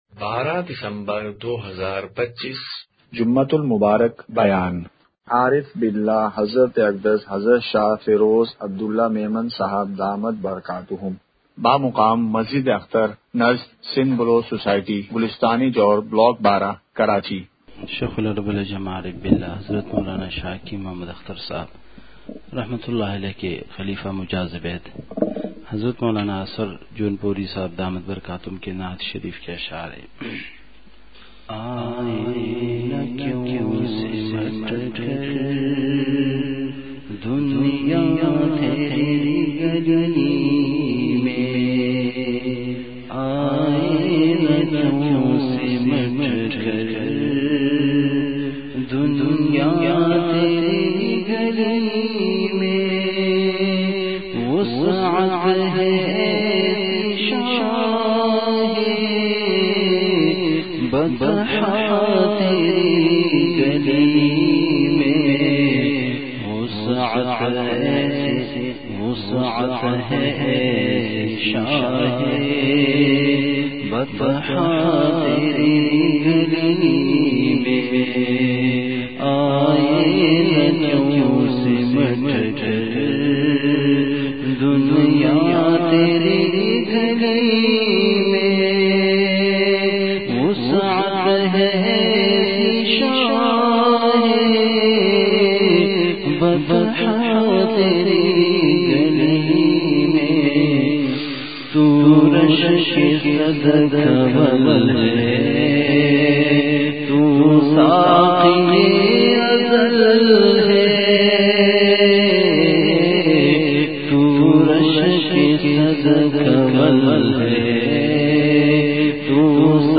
جمعہ بیان ۱۲ دسمبر ۲۵ء:میدانِ مزید اور علماء کا مقام !
مقام:مسجد اختر نزد سندھ بلوچ سوسائٹی گلستانِ جوہر کراچی